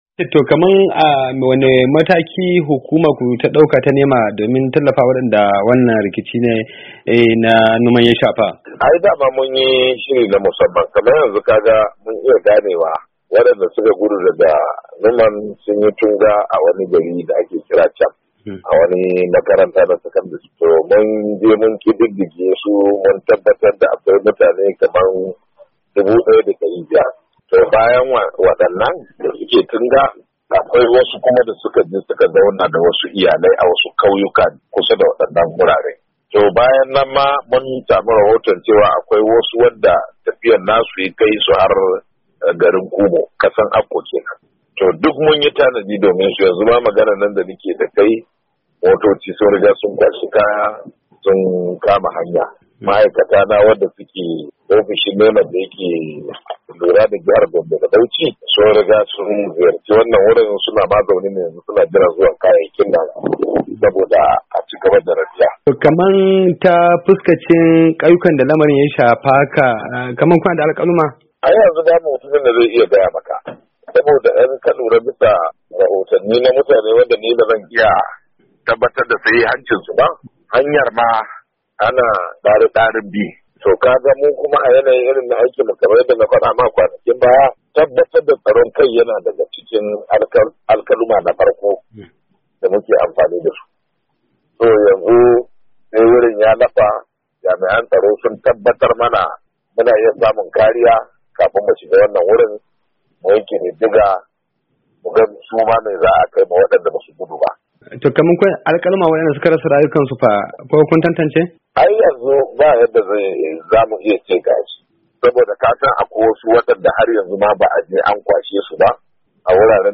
A wata hira da sashin Hausa ta wayar tarho